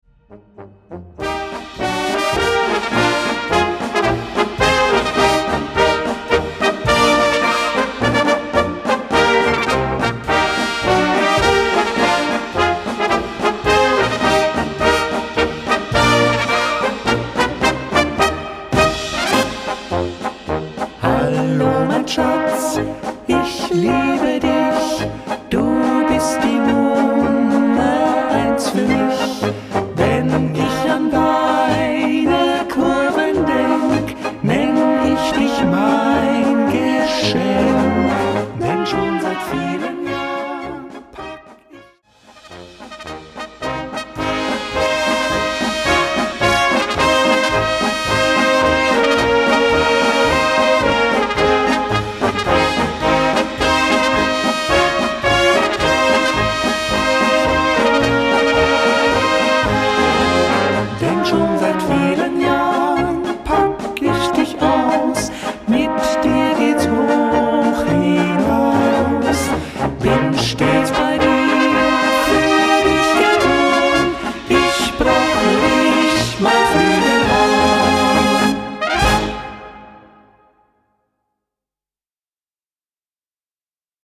Physisch und digital für Blasorchester erhältlich.